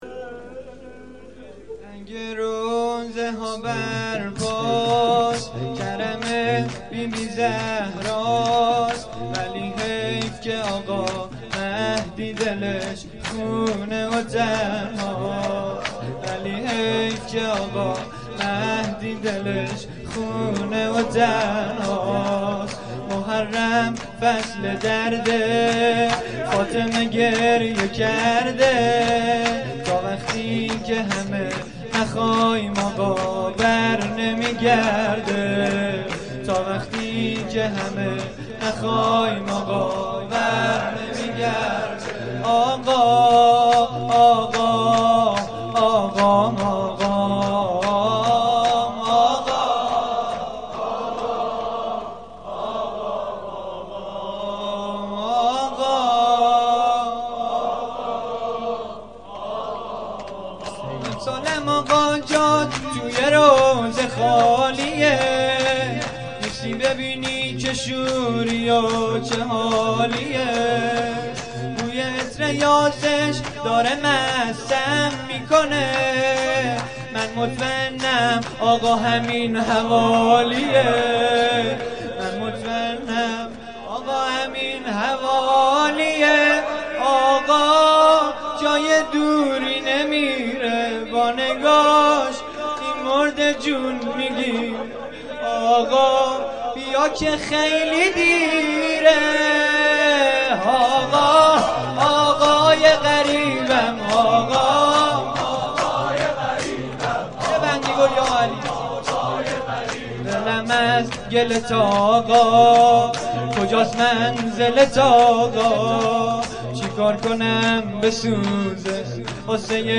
جلسه مذهبی زیارت آل یاسین باغشهر اسلامیه
شور